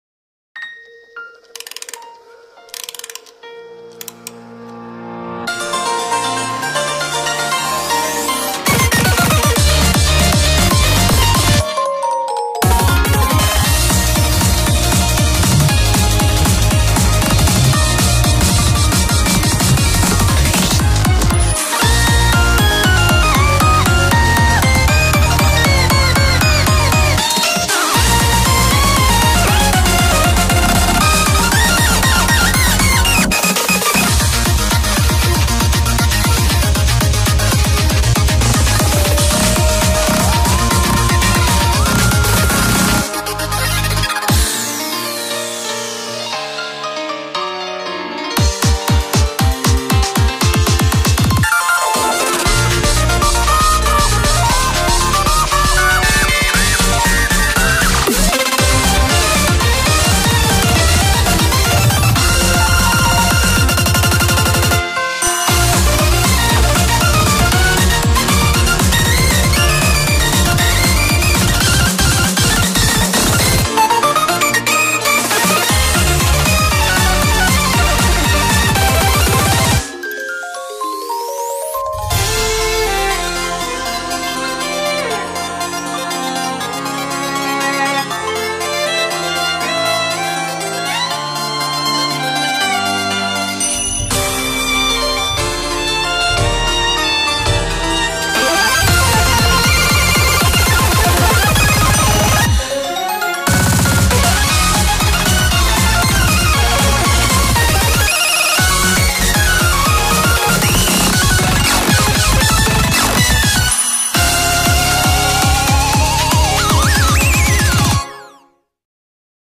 BPM48-260